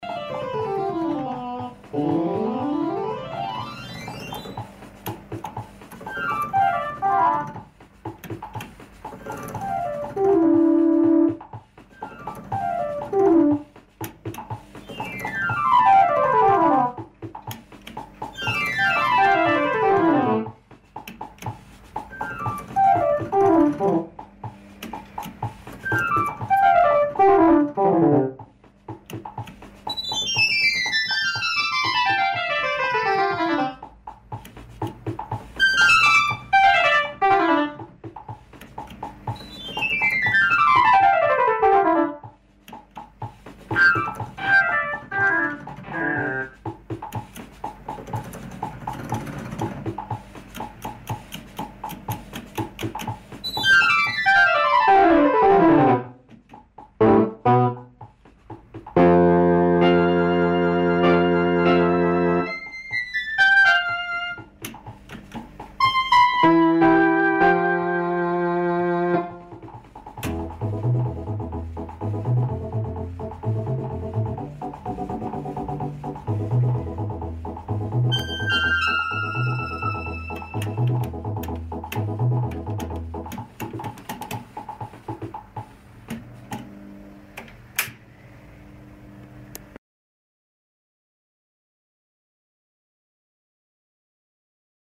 Italian transistor organ from early seventies with internal drum machine and auto accompaniment presets.
edit LFO a simple vibrato
Synthesis: transistor
lofi demo